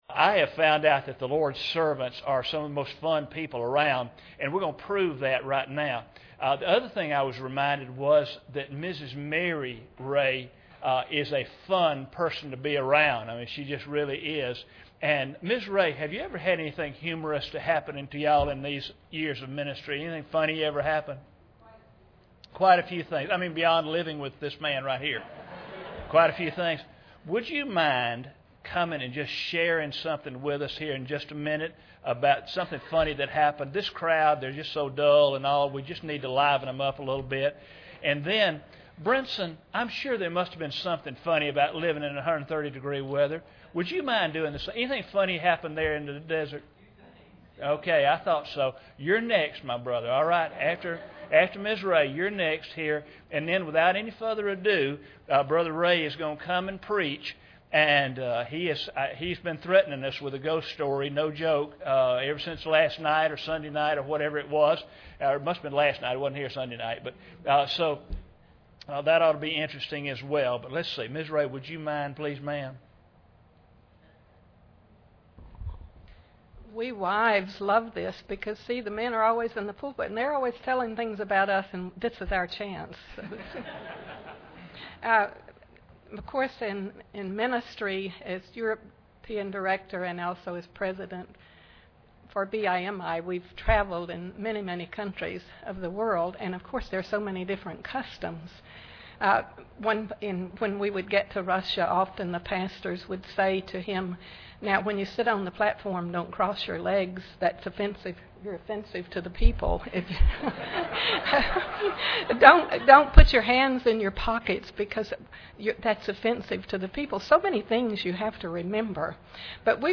Passage: John 2:1-10 Service Type: Special Service